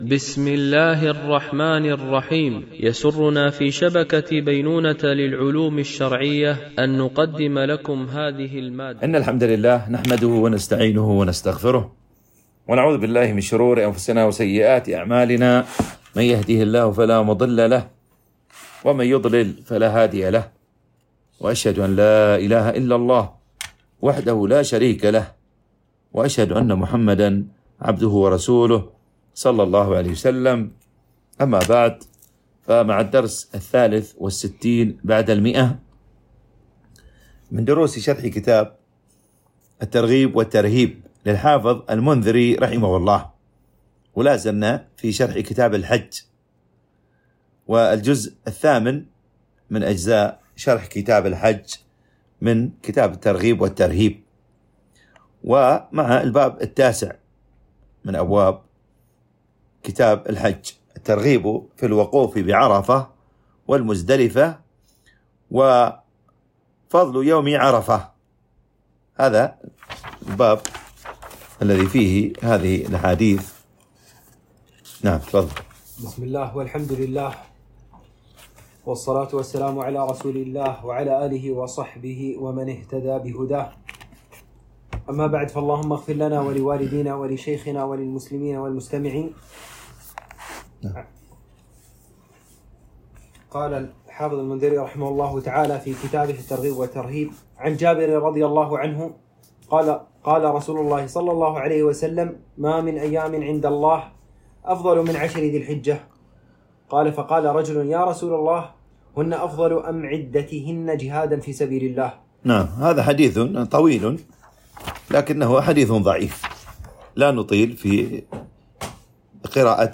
التنسيق: MP3 Mono 44kHz 96Kbps (VBR)